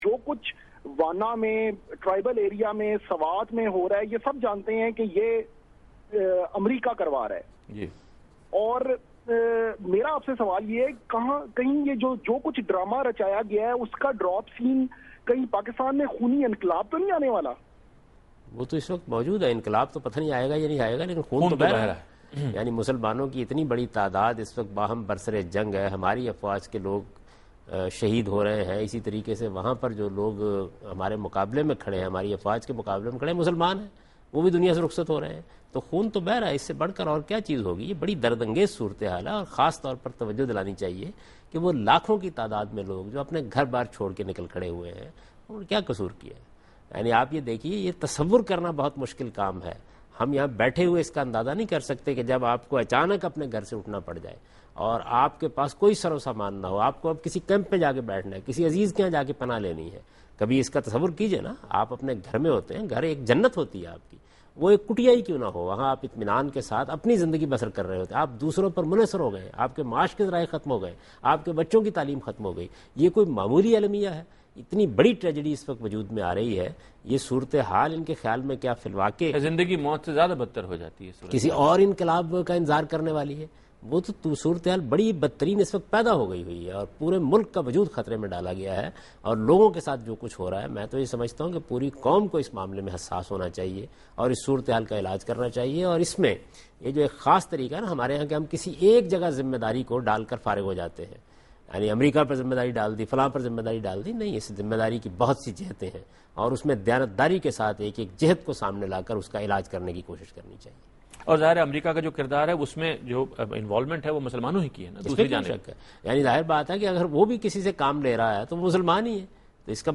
Answer to a Question by Javed Ahmad Ghamidi during a talk show "Deen o Danish" on Duny News TV
دنیا نیوز کے پروگرام دین و دانش میں جاوید احمد غامدی ”قبائلی علاقوں کی صورت حال اور خونی انقلاب“ سے متعلق ایک سوال کا جواب دے رہے ہیں